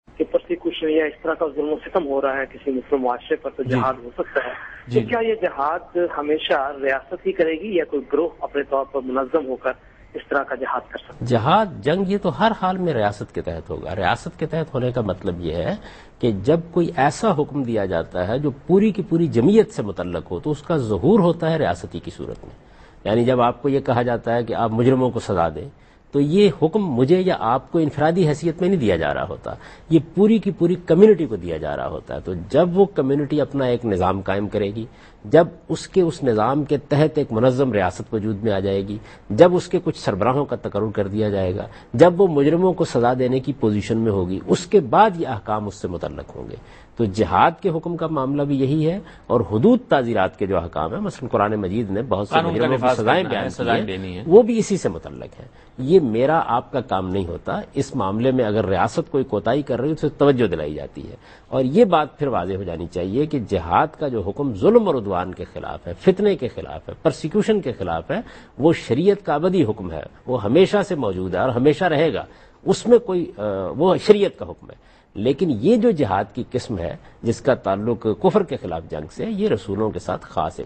Javed Ahmad Ghamidi answers a question in program Deen o Daanish on Dunya News.
کیا کوئی گروہ جہاد کر سکتا ہے؟ جاوید احمد غامدی دنیا نیوز کے پروگرام دین و دانش میں اس سوال کا جواب دے رہے ہیں۔